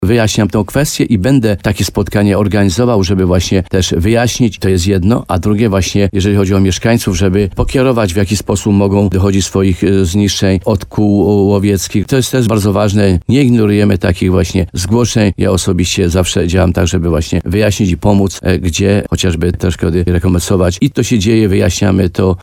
Jak powiedział radiu RDN Nowy Sącz starosta limanowski Mieczysław Uryga, rola urzędników w takich przypadkach jest podwójna.